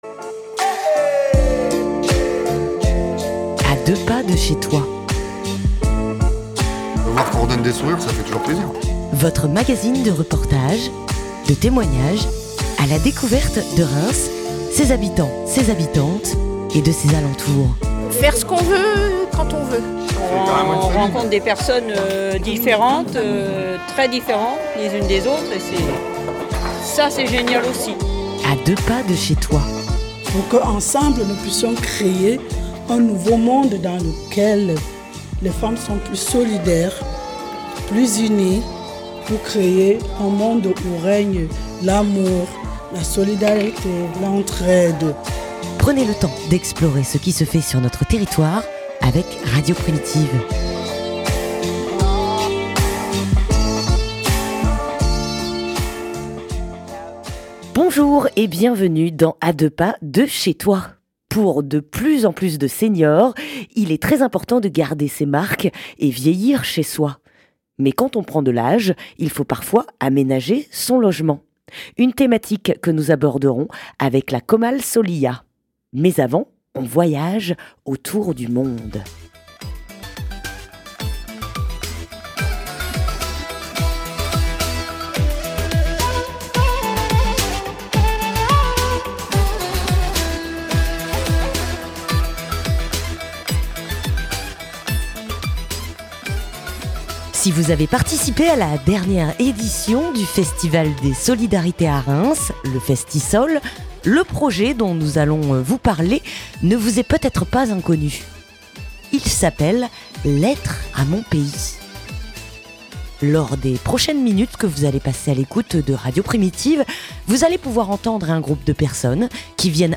Cette semaine nous vous présentons un projet réalisés par les apprenants de la langue française sur notre quartier d'Orgeval à Reims. Lettres à mon Pays a été présenté lors du dernier festival des solidarités, quelques mots pour leur pays d'origine, pays de coeur mis en voix dans les studios de Radio Primitive (de 00:00 à 24:00).